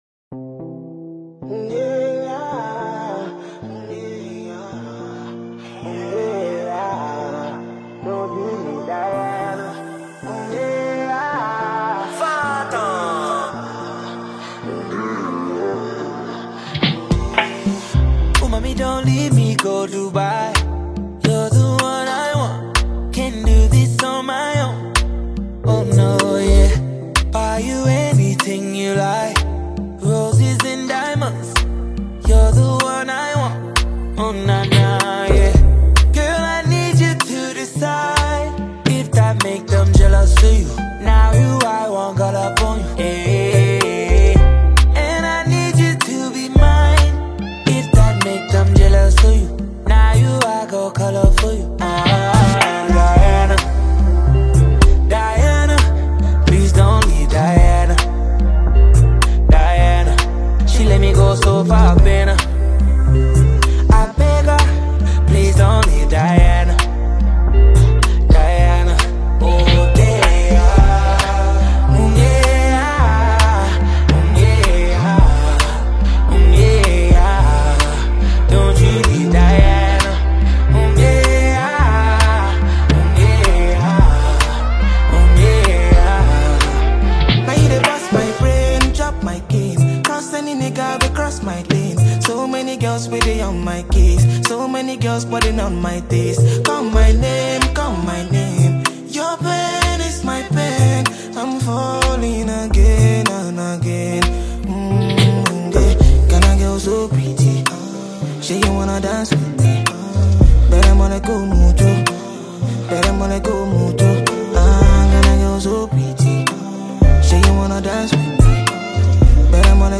Afrobeat
With its upbeat tempo and catchy sounds